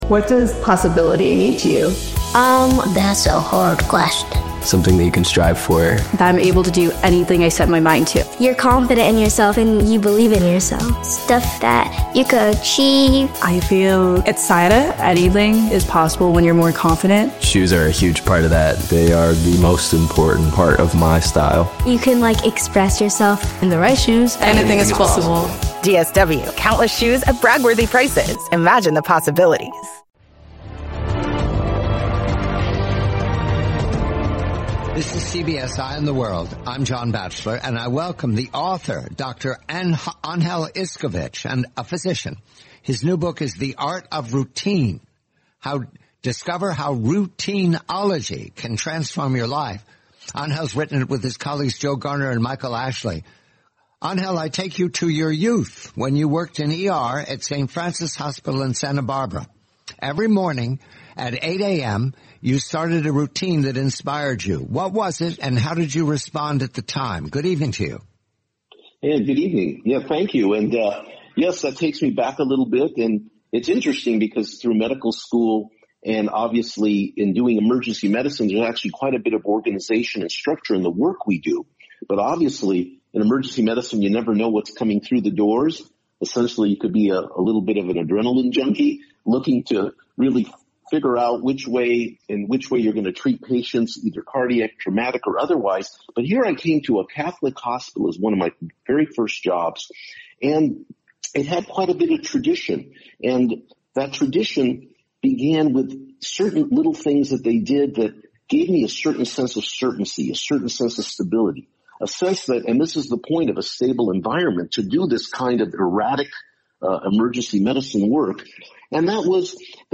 Routinology: the complete, twenty-minute interview.